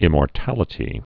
(ĭmôr-tălĭ-tē)